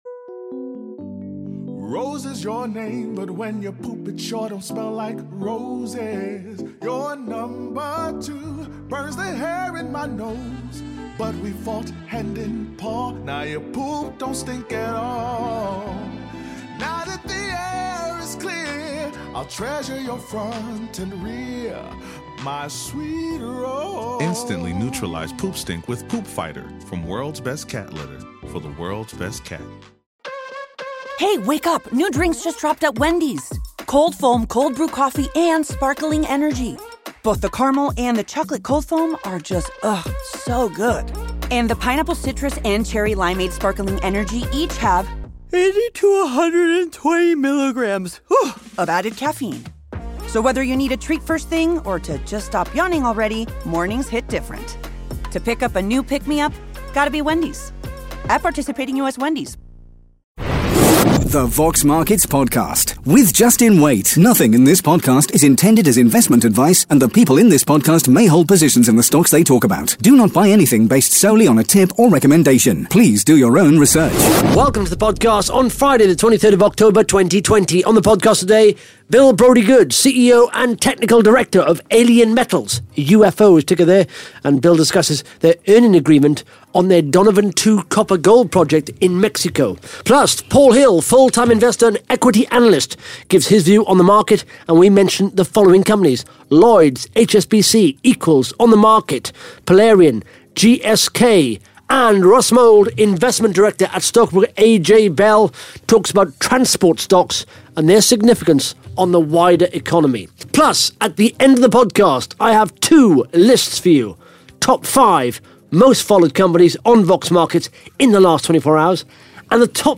(Interview starts at 16 minutes 20 seconds)